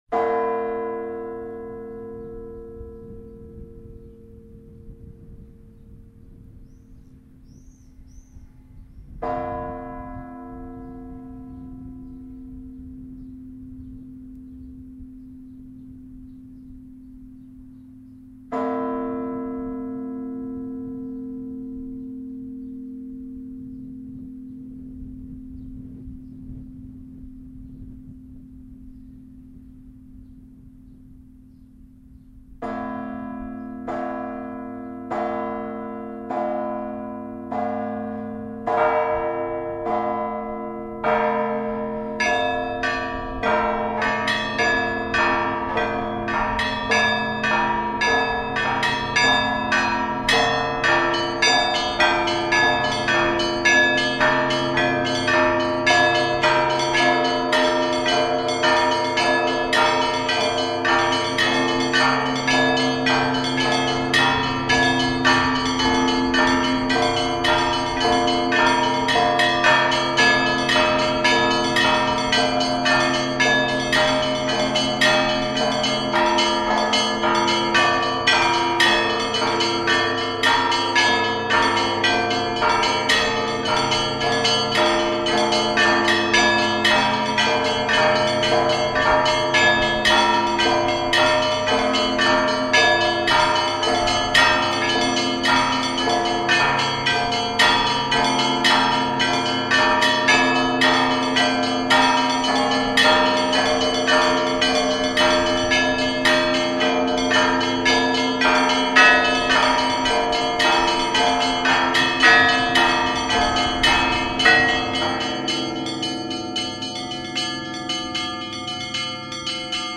Иллюстрация к статье "Рождество Христово". Рождественский колокольный звон